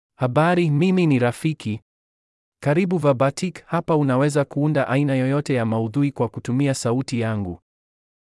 Rafiki — Male Swahili (Kenya) AI Voice | TTS, Voice Cloning & Video | Verbatik AI
Rafiki is a male AI voice for Swahili (Kenya).
Voice sample
Listen to Rafiki's male Swahili voice.
Rafiki delivers clear pronunciation with authentic Kenya Swahili intonation, making your content sound professionally produced.